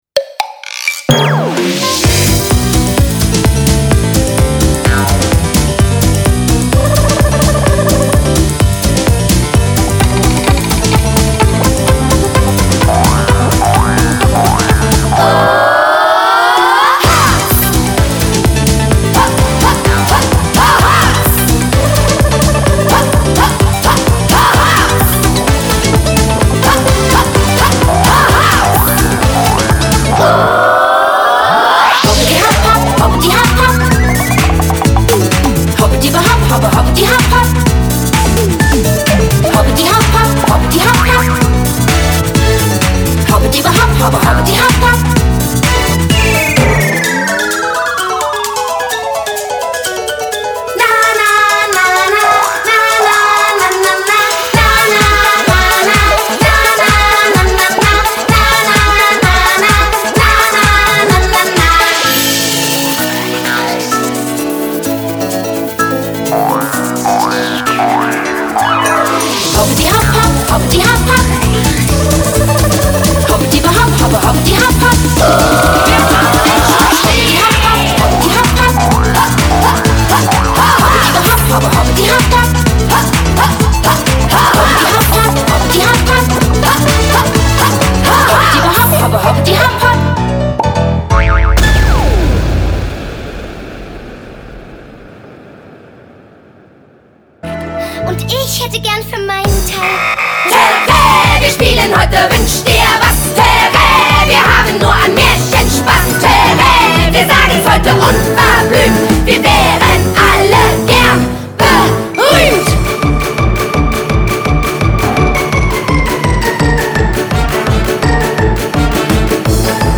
Snippet 3 – Gaga-Songs